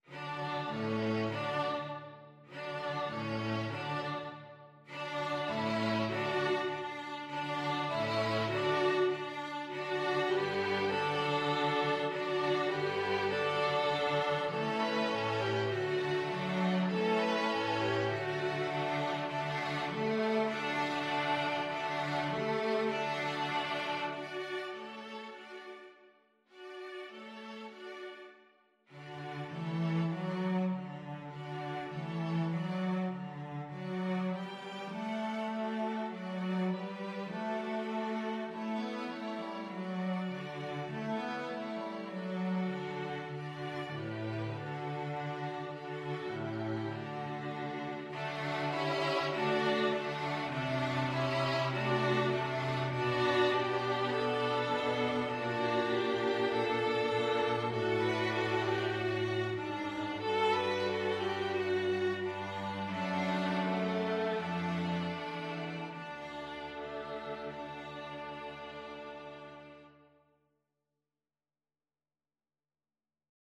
Violin 1Violin 2ViolaCello
D major (Sounding Pitch) (View more D major Music for String Quartet )
4/4 (View more 4/4 Music)
String Quartet  (View more Beginners String Quartet Music)
Traditional (View more Traditional String Quartet Music)
frere_jac_STRQ.mp3